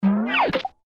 MG_pairing_jumping_signal.ogg